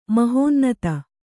♪ mahōnnata